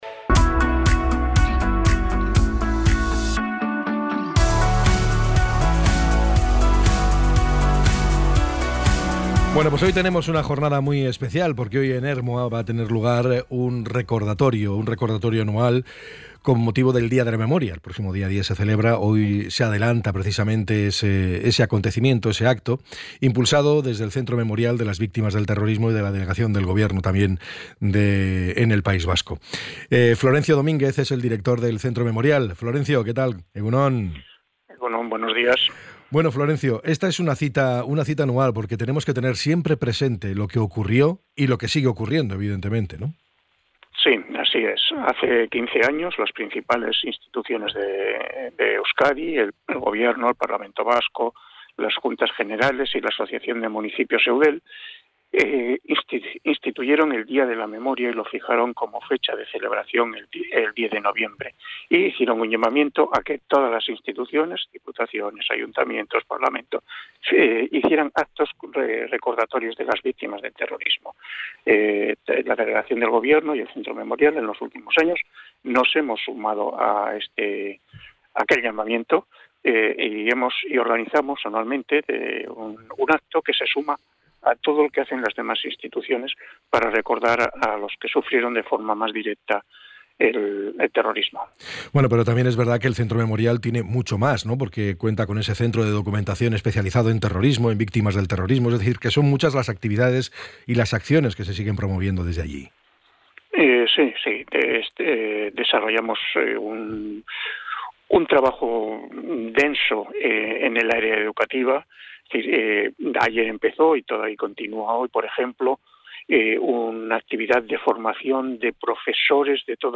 ENTREV.-FLORENCIO-DOMINGUEZ.mp3